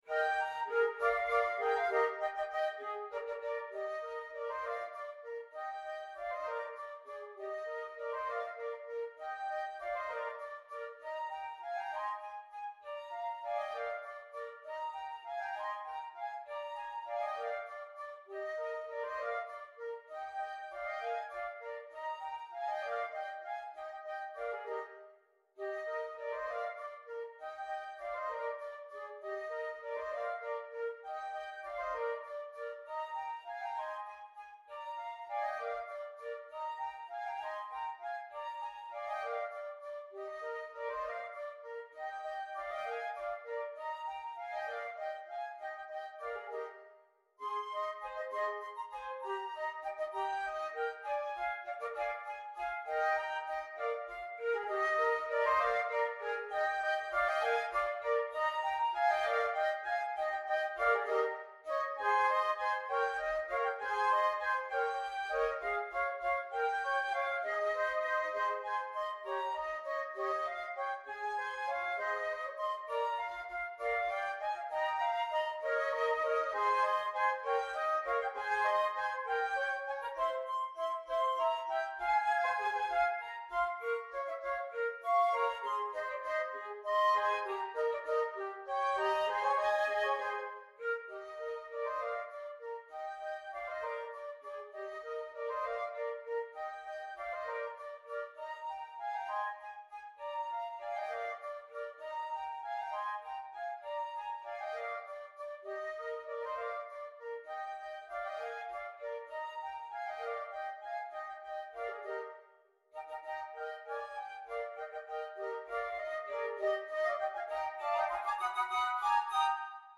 set for 3 like-instruments